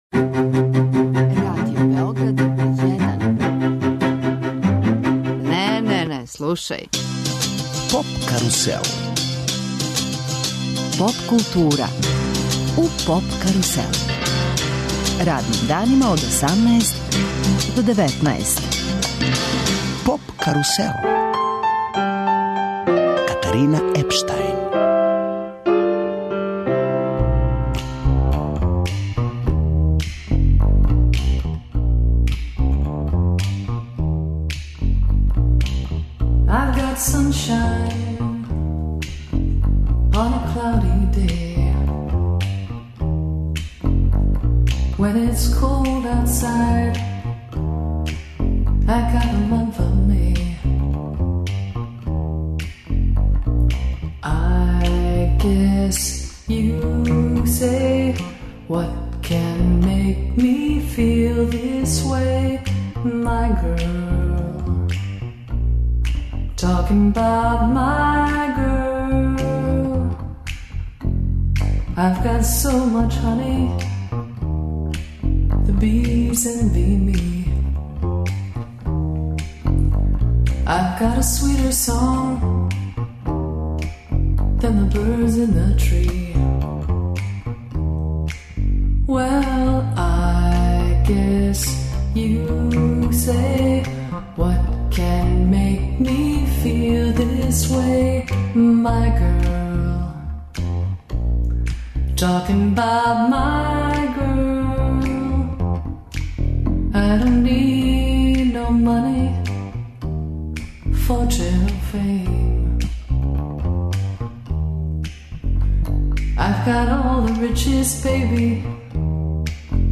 Гост емисије је композитор и пијаниста Васил Хаџиманов. После успешног наступа на Коларцу у фебруару са Бојаном Зулфикарпашићем, у најави су два октобарска заједничка концерта ова два уметника.